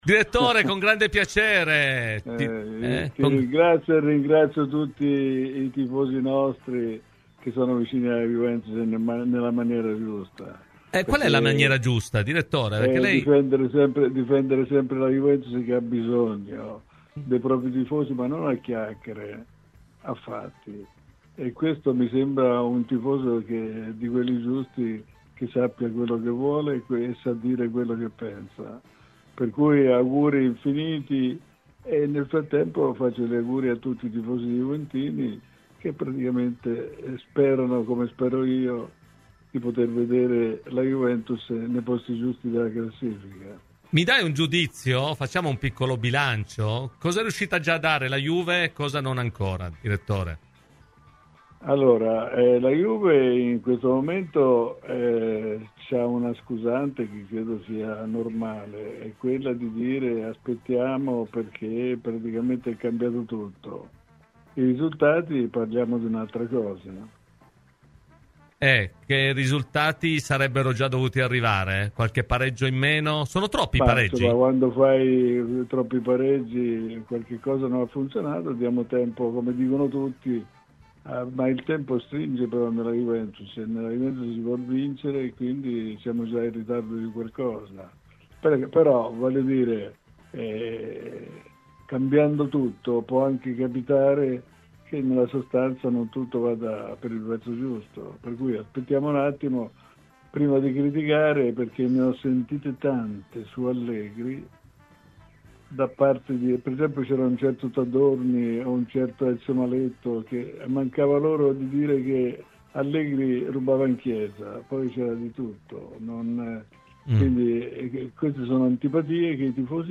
Intervenuto ai microfoni di Radio Bianconera, l'ex dirigente della Juventus Luciano Moggi ha parlato del momento dei bianconeri: "La Juventus ha sempre bisogno dei propri tifosi che devono impegnarsi a difenderla.